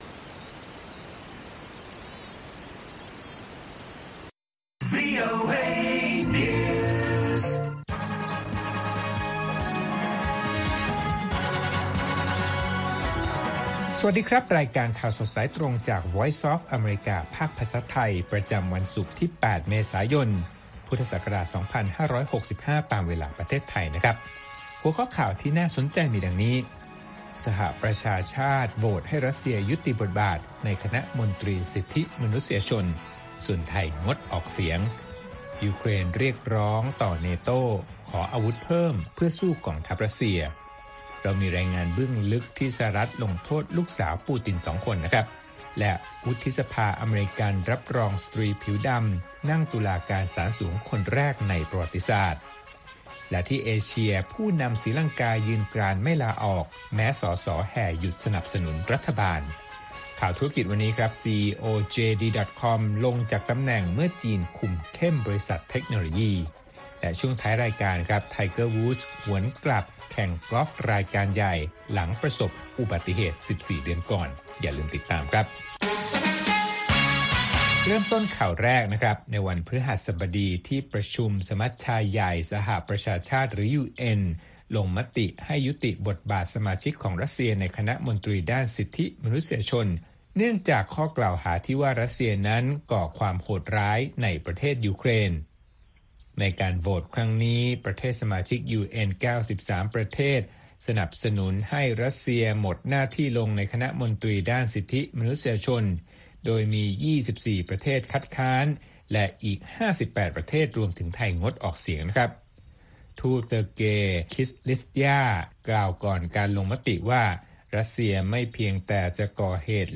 ข่าวสดสายตรงจากวีโอเอ ภาคภาษาไทย ประจำวันศุกร์ที่ 8 เมษายน 2565 ตามเวลาประเทศไทย